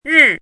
汉字“驲”的拼音是：rì。
“驲”读音
驲字注音：ㄖˋ
国际音标：ʐʅ˥˧